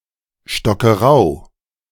ʃtɔkɐˈʁaʊ̯, česky Štokrava[3]) je město v Rakousku ve spolkové zemi Dolní Rakousko v okrese Korneuburg.
De-Stockerau.ogg